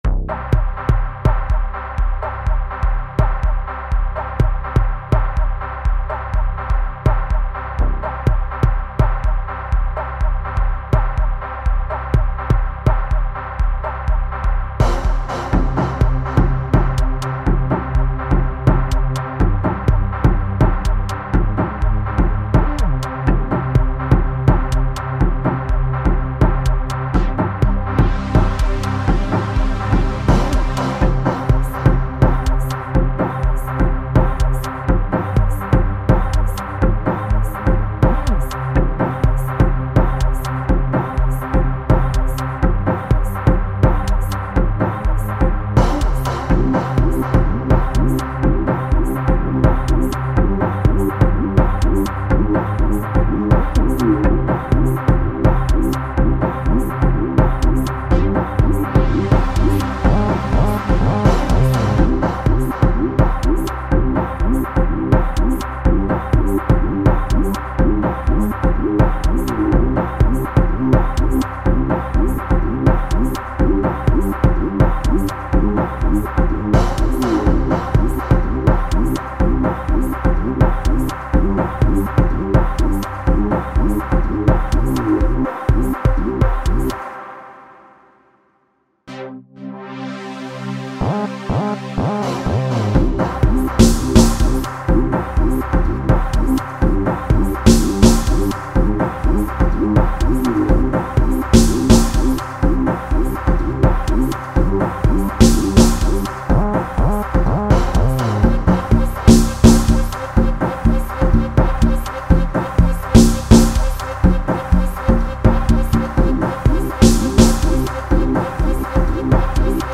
African Music You may also like